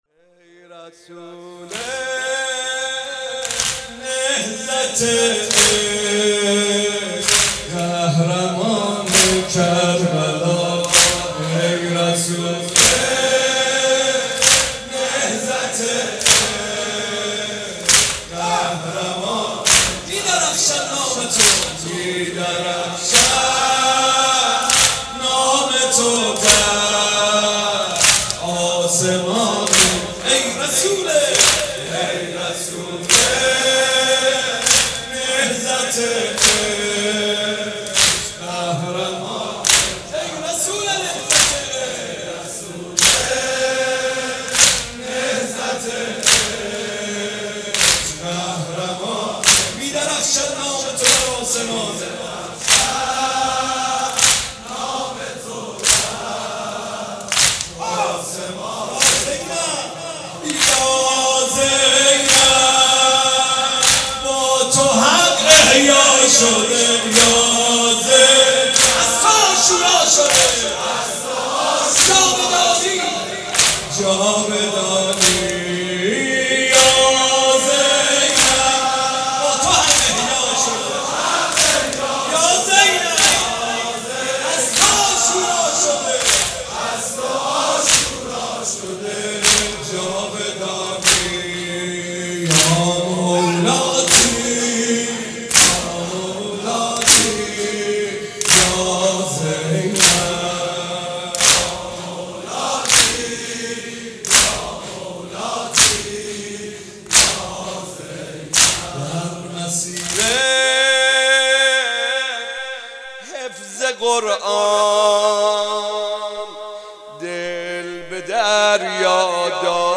مداح : محمدرضا طاهری قالب : واحد